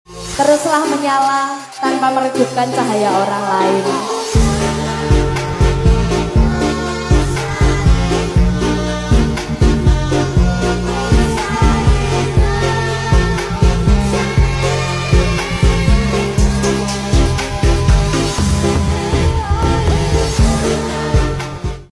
Masuk papan untuk lantai rumah sound effects free download